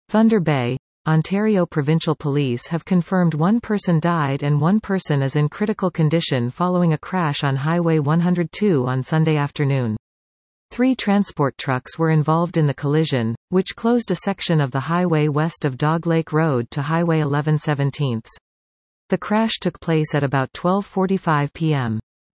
Listen to this article 00:00:22 THUNDER BAY -- Ontario Provincial Police have confirmed one person died and one person is in critical condition following a crash on Highway 102 on Sunday afternoon.